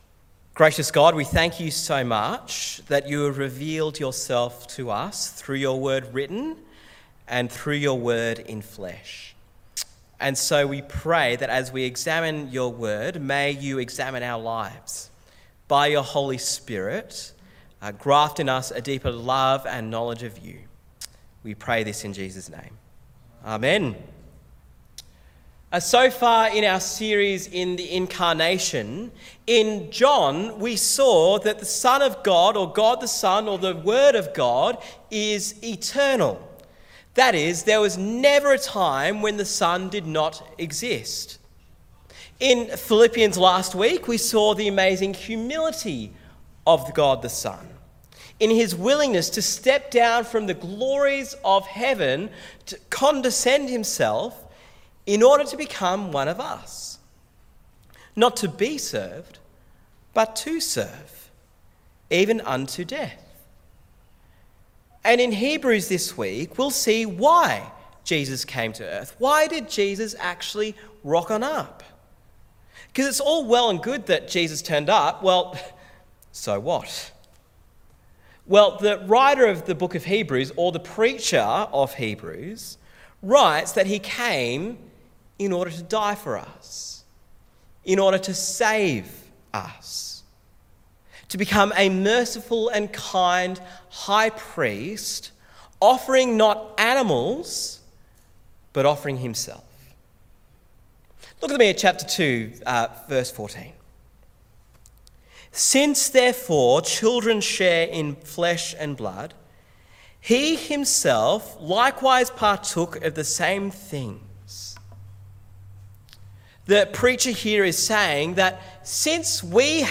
Listen to the sermon on Hebrews 2:14-18 in our The Incarnation series.